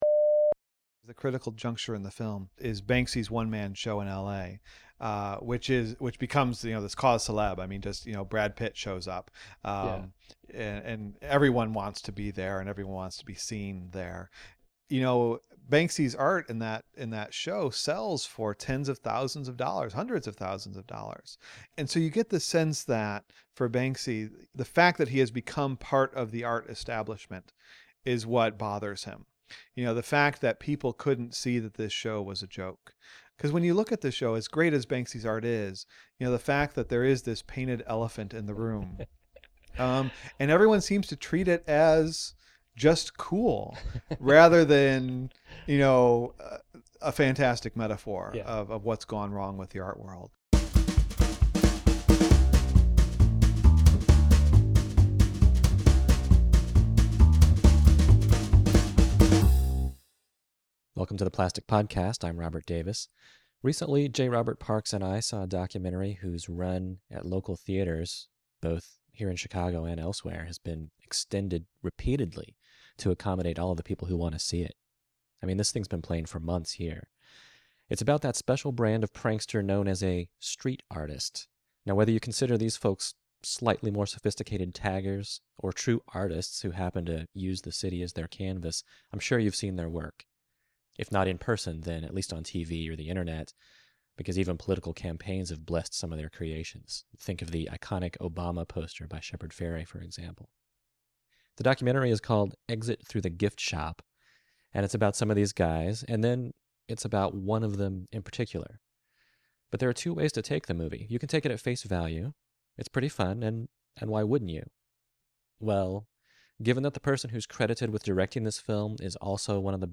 0:00 Intro 2:19 Discussion: Exit Through the Gift Shop (Banksy, 2010) 27:19 Outro